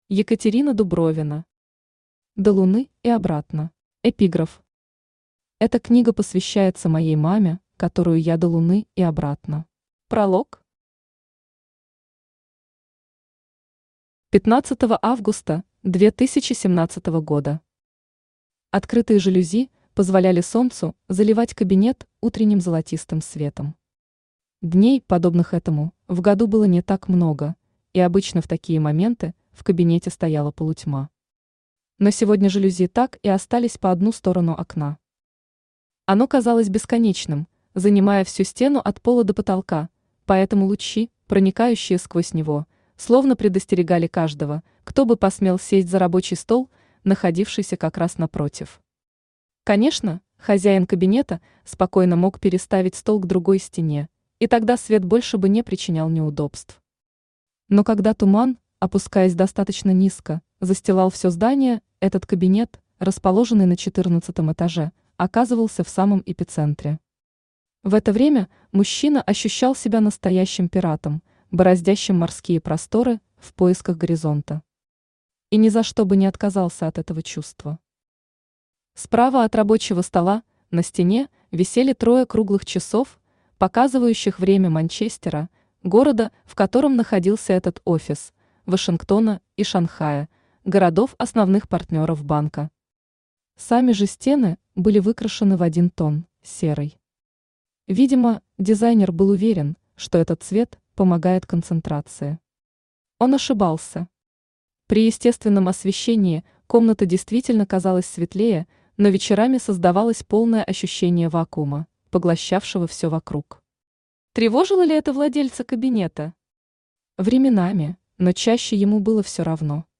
Аудиокнига До Луны и обратно | Библиотека аудиокниг
Aудиокнига До Луны и обратно Автор Екатерина Дубровина Читает аудиокнигу Авточтец ЛитРес.